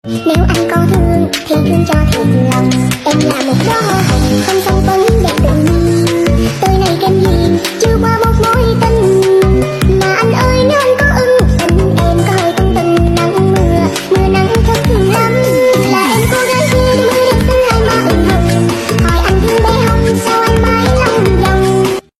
Remix Tiktok